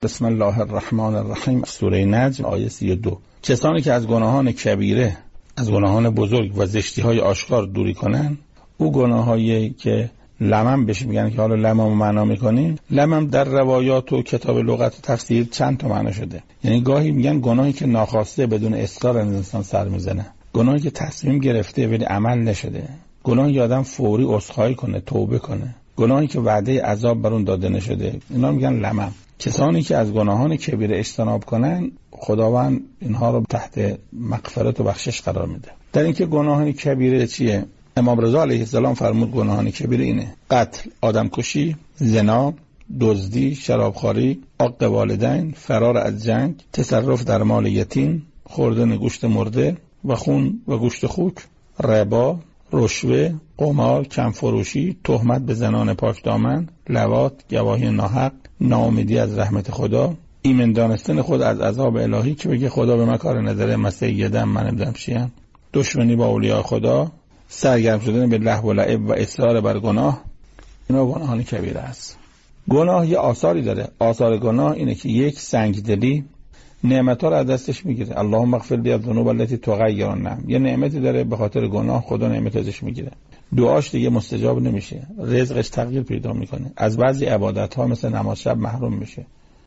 در برنامه «شرح و توضیح تلاوت» از گروه علوم قرآنی رادیو قرآن با كارشناسی استاد محسن قرائتی، آیه 32 سوره نجم مورد بررسی قرار گرفت. در این تفسیر، علاوه بر تبیین معنای «لمم»، به مصادیق گناهان كبیره و آثار زیان‌بار آن‌ها بر زندگی انسان پرداخته شد.